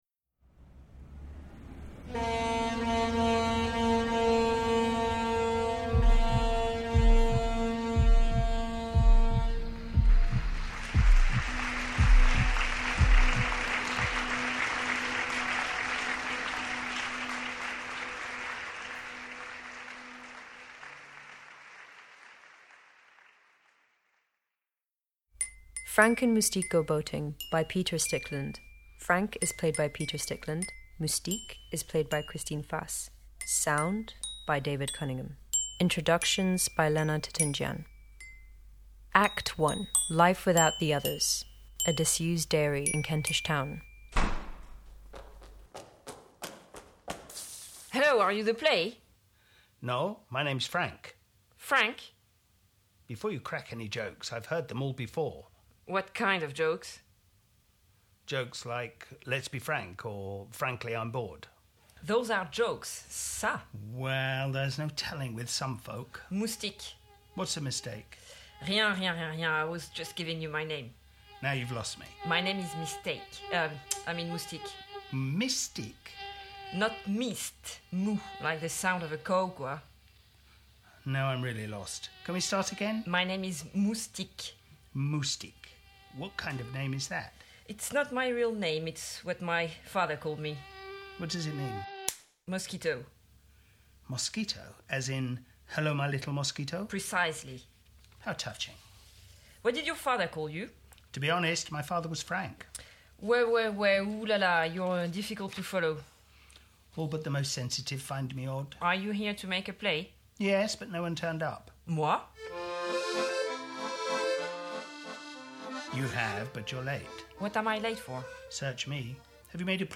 ‘Frank and Moustique Go Boating’ and ‘Frank and Moustique Get a Life’ [Comedy - two audio plays] - UAL Research Online